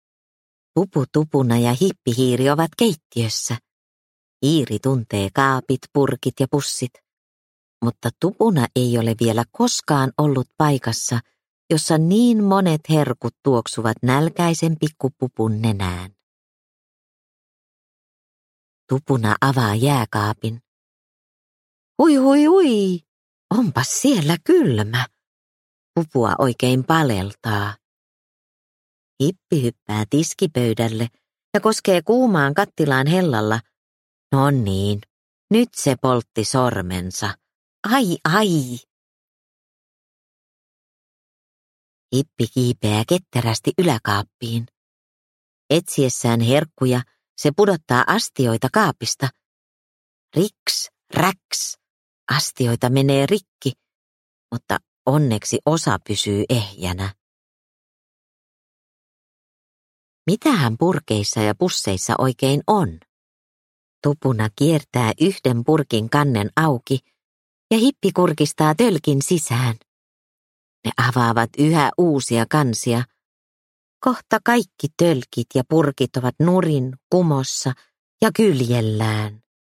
Pupu Tupuna vauhdissa – Ljudbok – Laddas ner